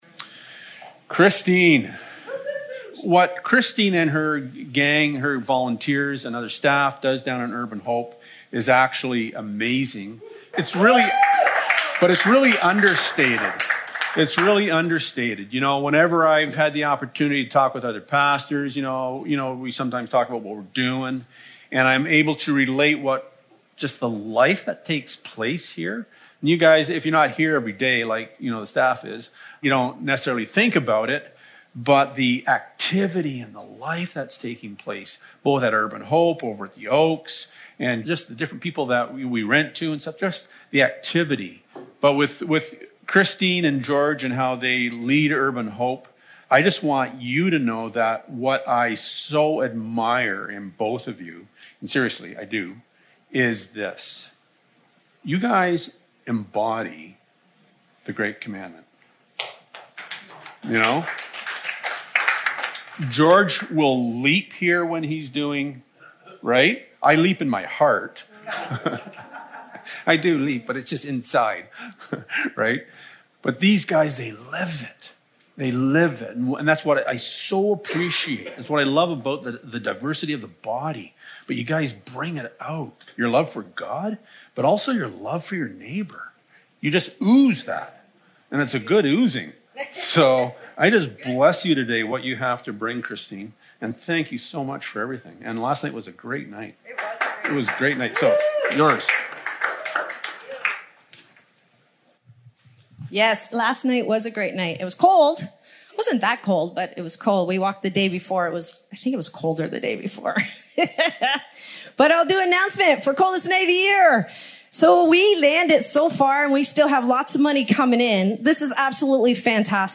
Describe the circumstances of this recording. Service Type: Sunday Morning Topics: Discipleship , Kingdom , obedience , Prayer « YOU ARE…Salt & Light Caution!